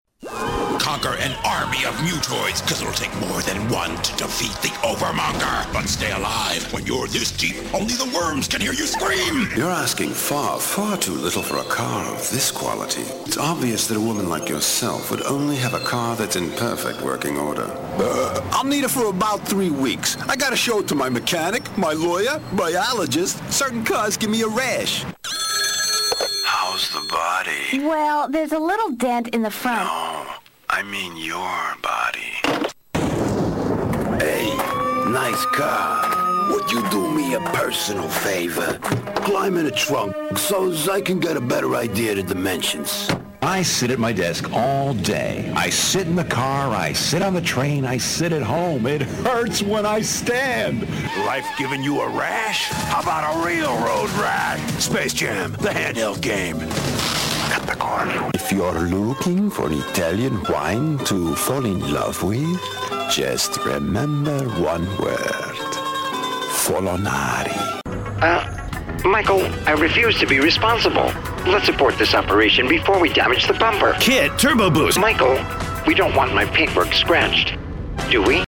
Bass / Baritone with textured and powerful voice. Very flexible from Dark and dramatic to warm and conversational.
Profi-Sprecher englisch (usa). Trailerstimme, Werbesprecher.
Sprechprobe: Sonstiges (Muttersprache):